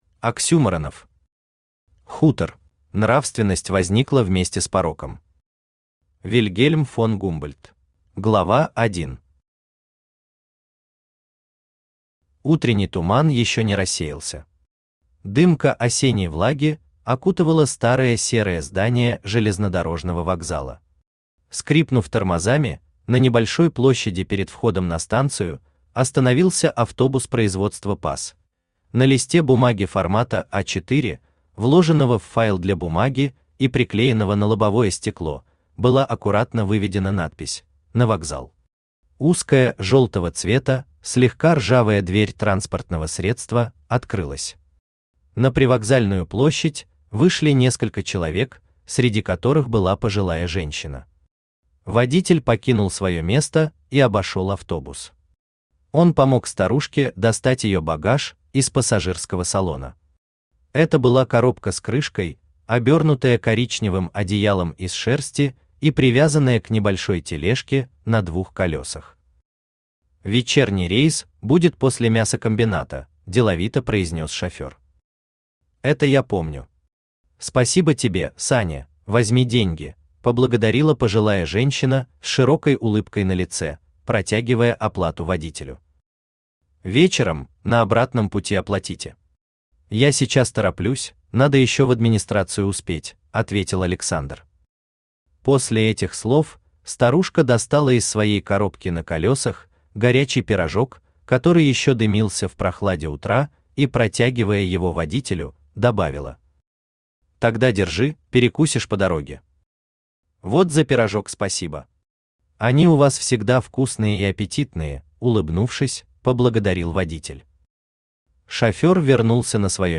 Аудиокнига Хутор | Библиотека аудиокниг
Aудиокнига Хутор Автор Оксюморонов Читает аудиокнигу Авточтец ЛитРес.